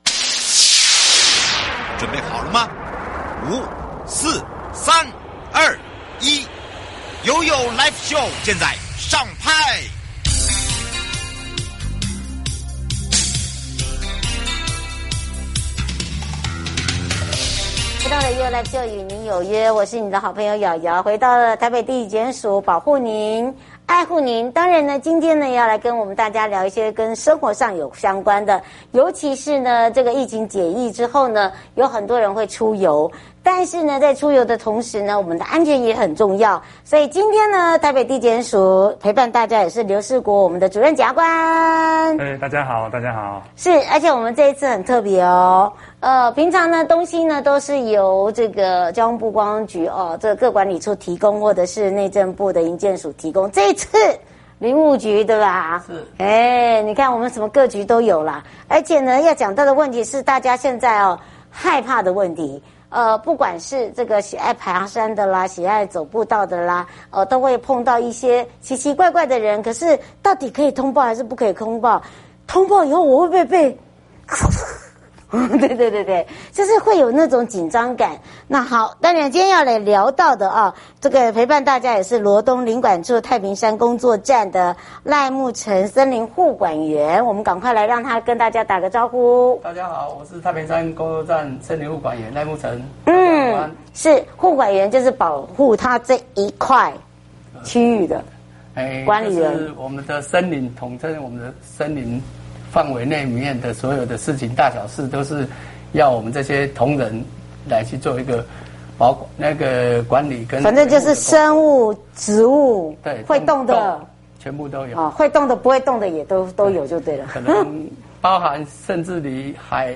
受訪者： (直播) 1.